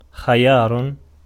ملف تاريخ الملف وصلات معلومات الصورة (ميتا) Ar-خيار.ogg  (Ogg Vorbis ملف صوت، الطول 1٫3ث، 105كيلوبيت لكل ثانية) وصف قصير ⧼wm-license-information-description⧽ Ar-خيار.ogg English: Pronunciation of word "خيار" in Arabic language. Male voice. Speaker from Tiznit, Morocco.
Ar-خيار.ogg.mp3